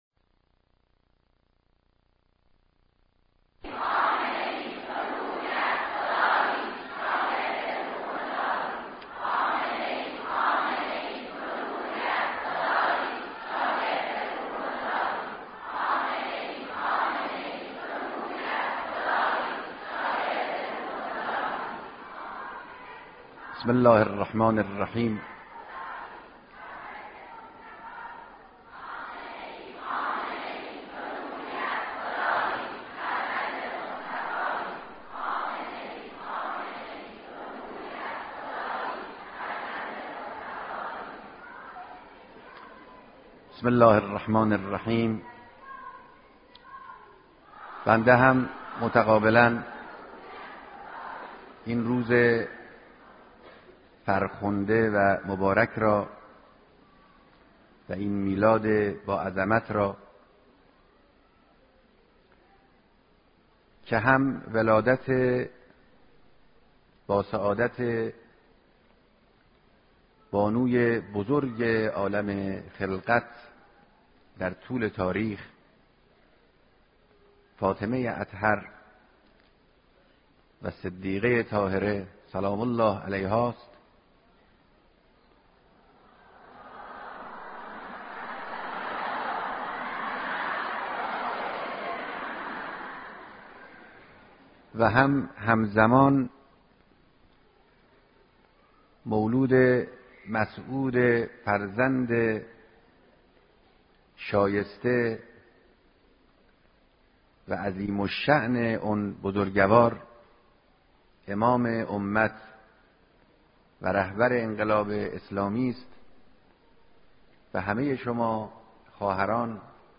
صوت کامل بیانات
سخنرانی